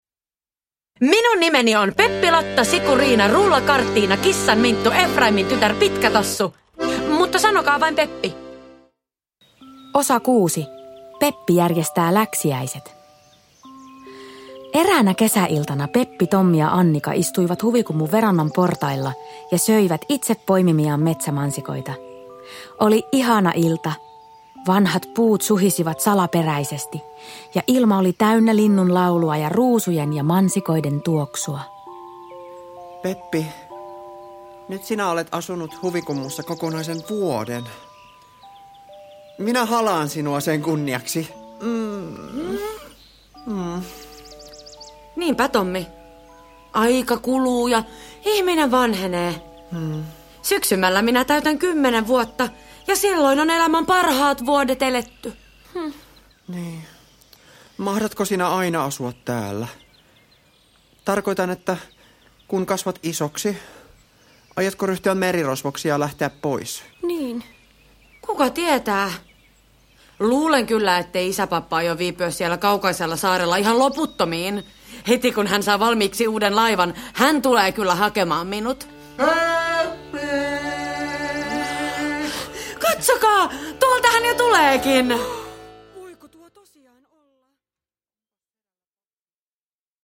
Peppi järjestää läksiäiset. Kuunnelma osa 6 – Ljudbok
Kaikille tuttu Peppi Pitkätossu ilahduttaa nyt eläväisinä ja hauskoina kuunnelmina.